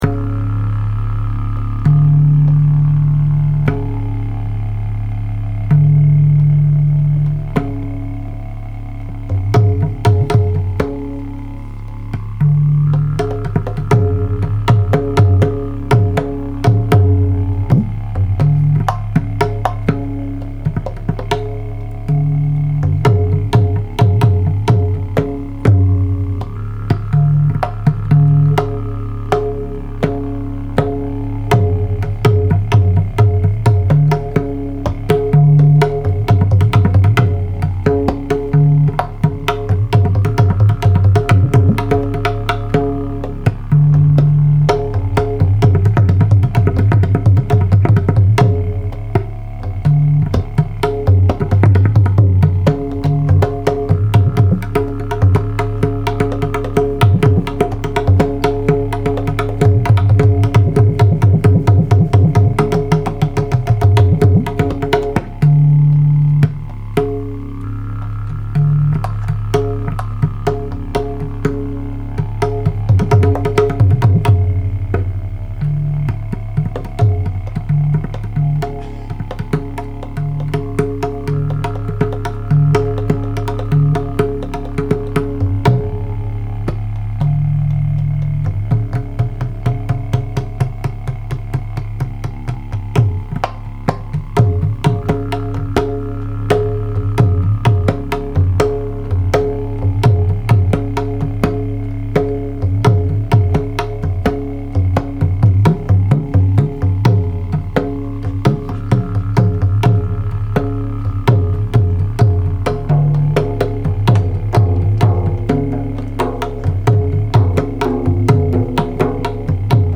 Live at The FlynnSpace
The piece begins with a pakhawaj solo
bendir
didjeridoo
baritone sax
soprano sax
trumpet